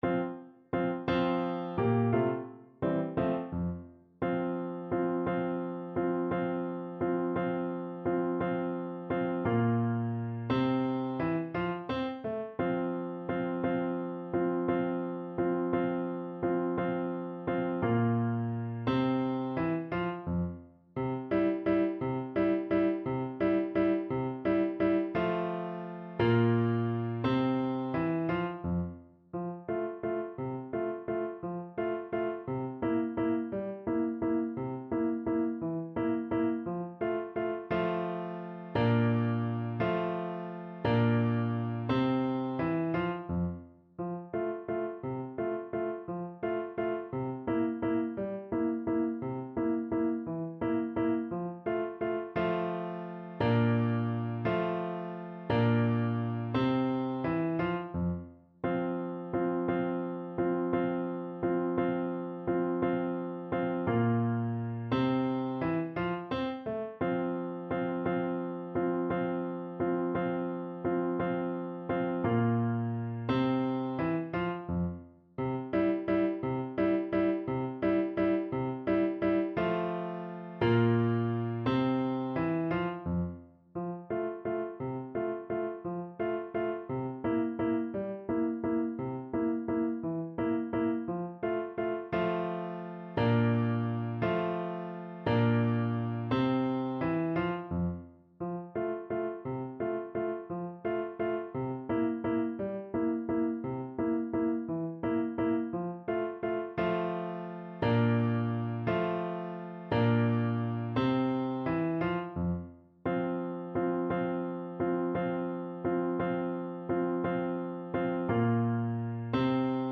kolęda: Północ już była (na klarnet i fortepian)
Symulacja akompaniamentu